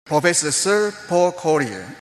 Here is a non-native saying Professor Sir Paul Collier:
There the word Sir is pronounced strongly.